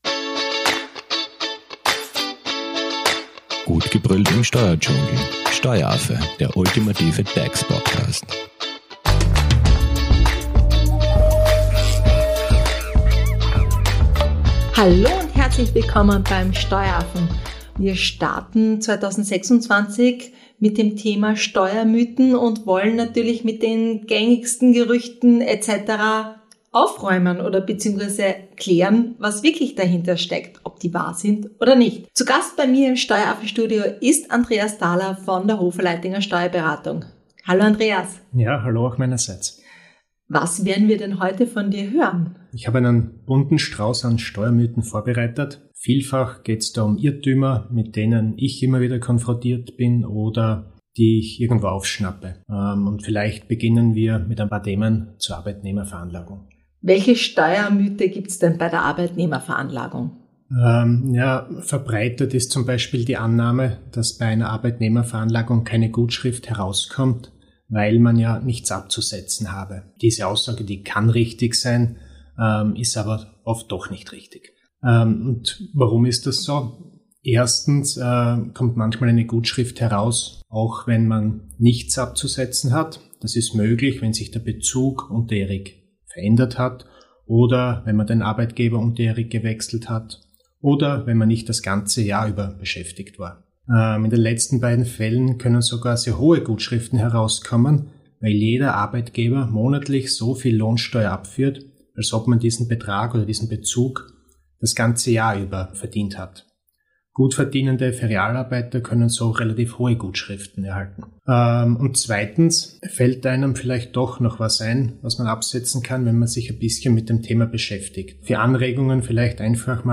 Ihr erfährt, warum auch bei einem geringen Einkommen eine Steuer-Gutschrift möglich ist, wie Sonderausgaben und Werbungskosten tatsächlich wirken und weshalb mehr verdienen auch „mehr bringt“. Zu Gast im Steueraffen-Studio